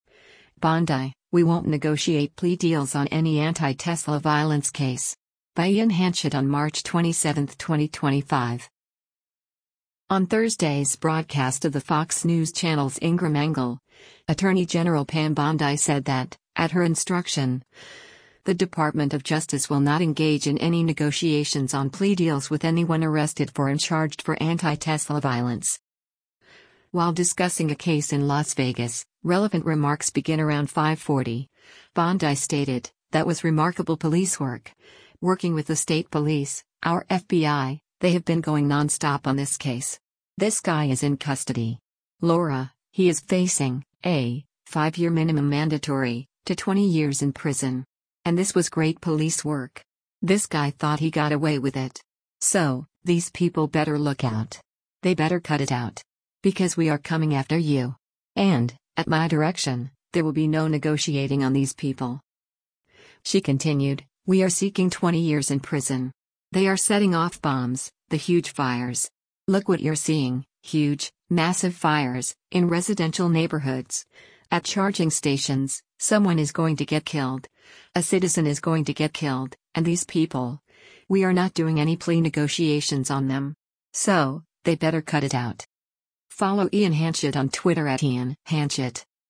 On Thursday’s broadcast of the Fox News Channel’s “Ingraham Angle,” Attorney General Pam Bondi said that, at her instruction, the Department of Justice will not engage in any negotiations on plea deals with anyone arrested for and charged for anti-Tesla violence.